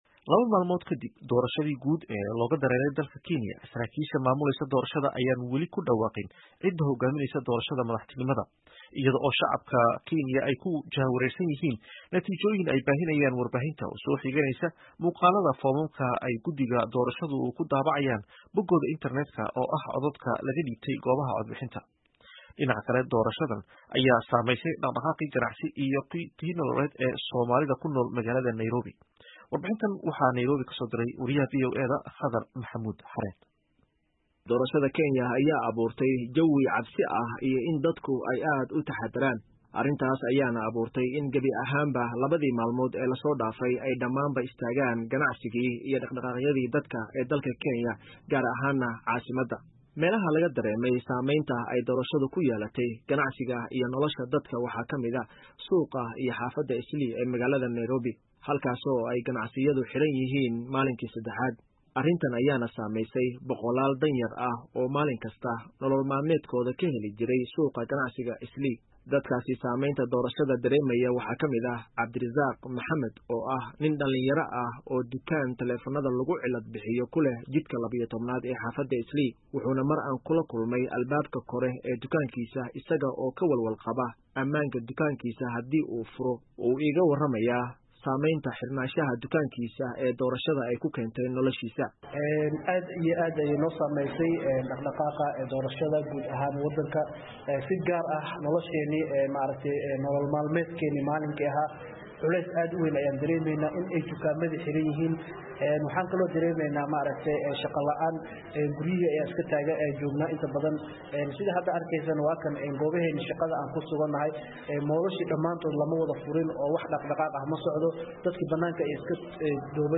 Nairobi —